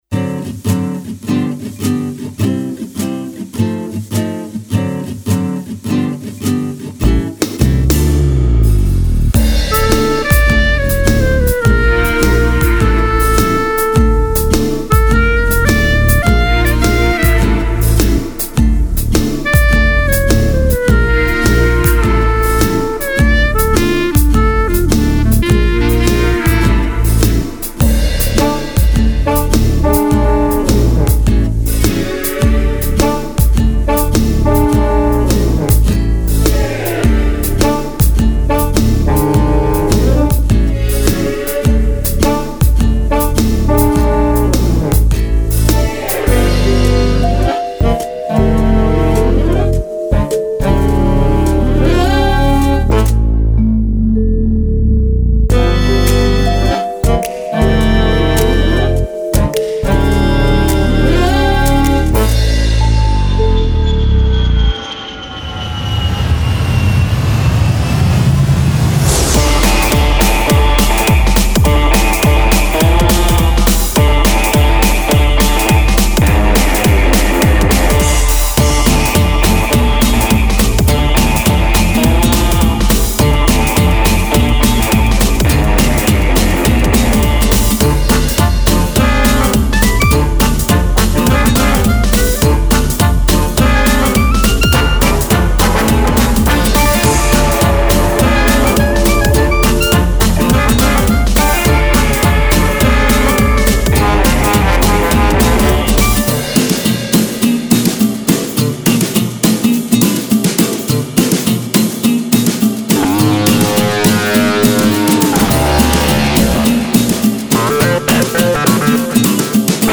Вещица написана для цирка (клоунада).